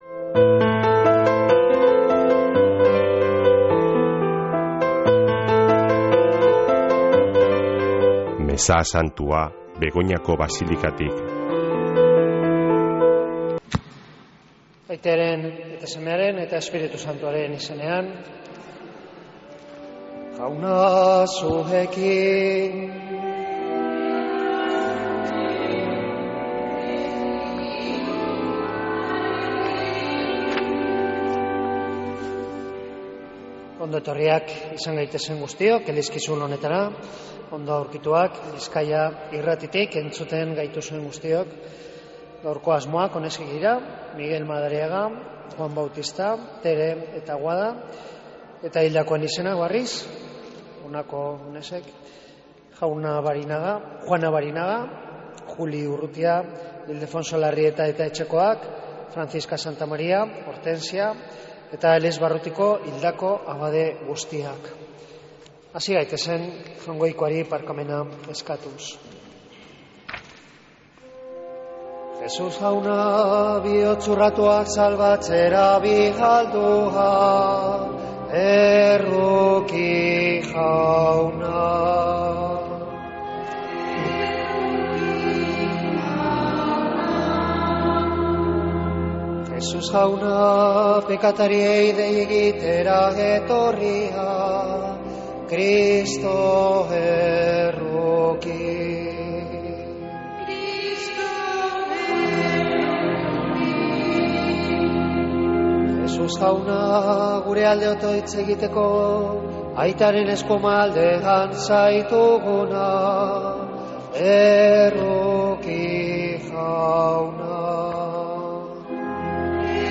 Mezea (24-11-04)
MEZEA.mp3